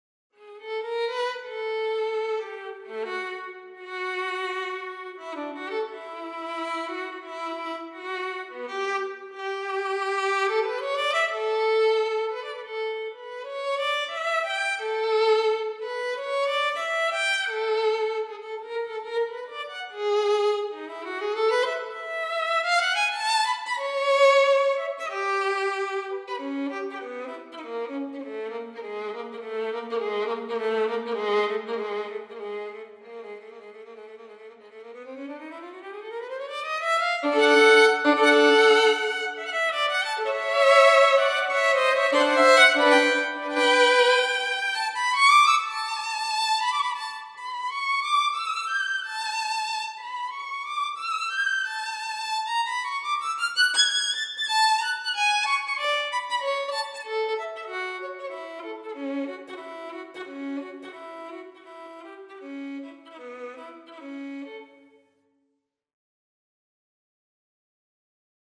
So I tried to change the color of the violin from Sample Modeling with a few plugin's, moreover out of interest in the possibilities.
Both version have reverb added (Fabfilter) though.